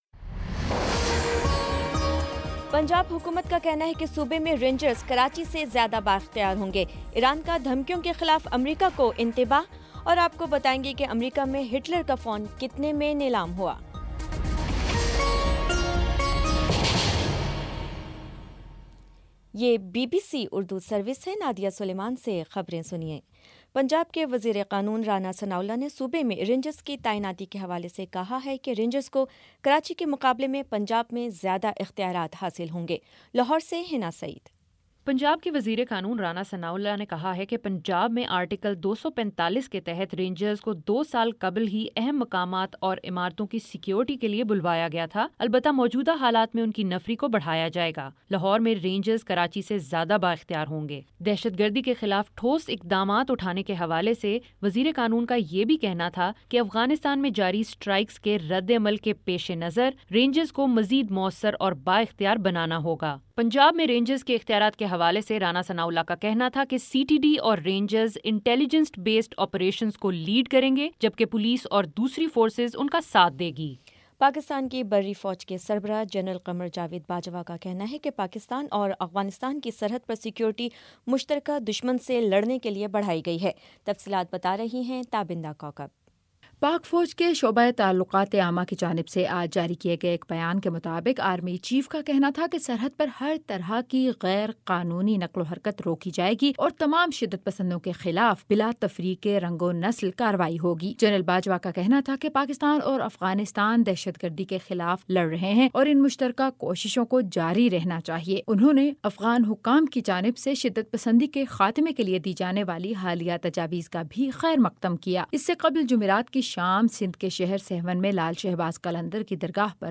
فروری 20 : شام چھ بجے کا نیوز بُلیٹن